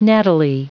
Prononciation du mot nattily en anglais (fichier audio)
Prononciation du mot : nattily